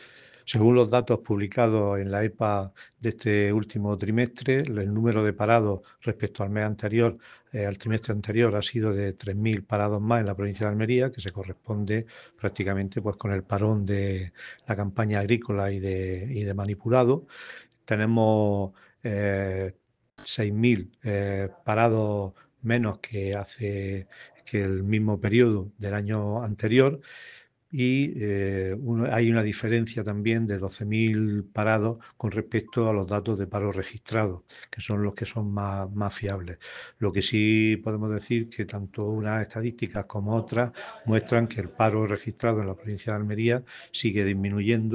Declaraciones.mp3